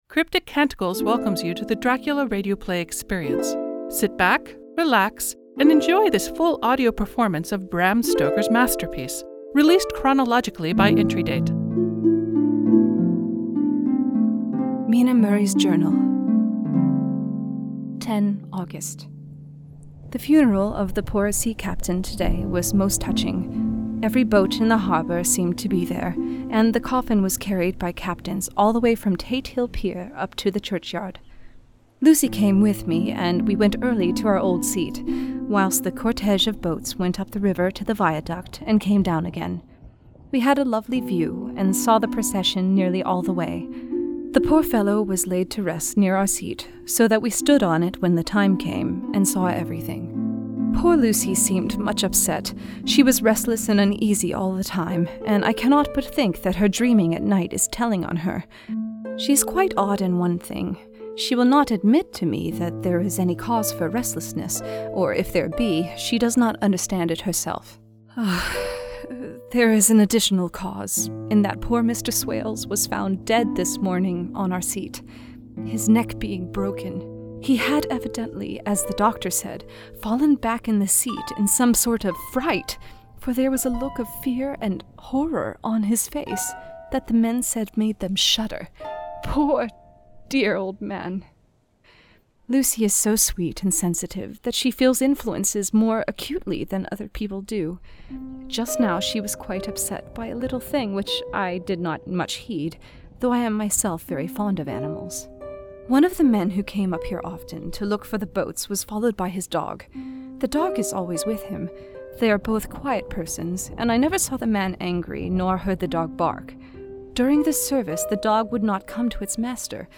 Audio Engineer, SFX and Music